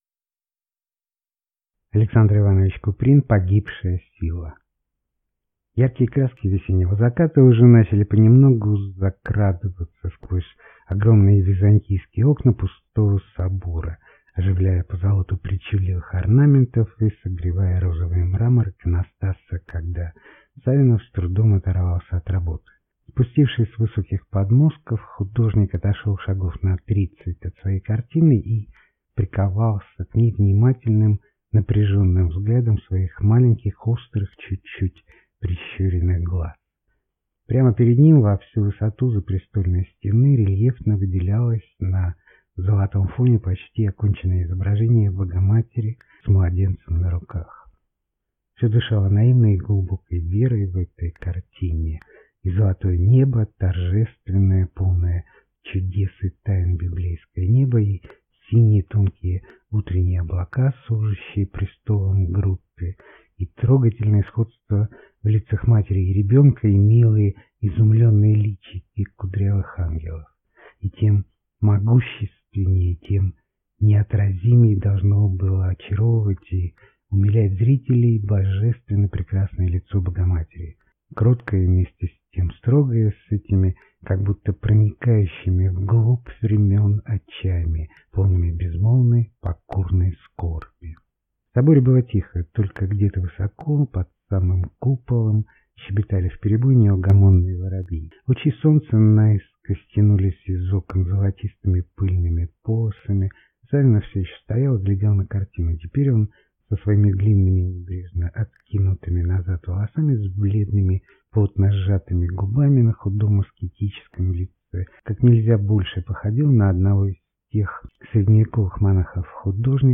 Аудиокнига Погибшая сила | Библиотека аудиокниг
Aудиокнига Погибшая сила Автор Александр Куприн